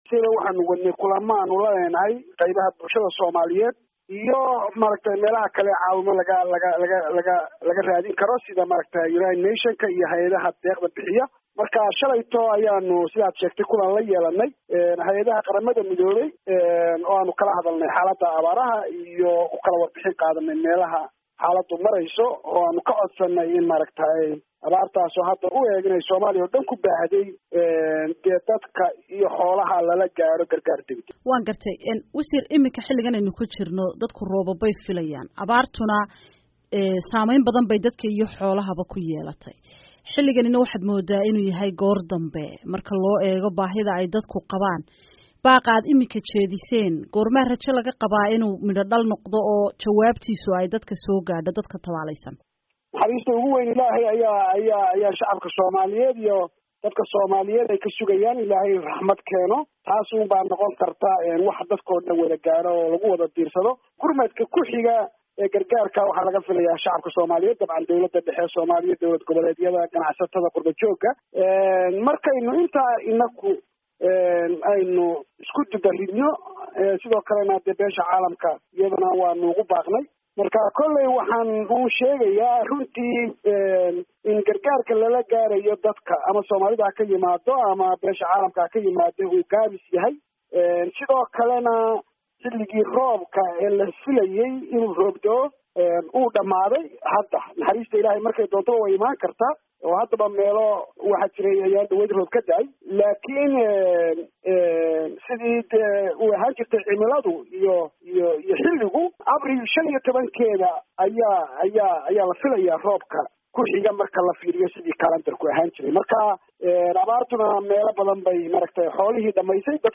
Wareysi: Maareeye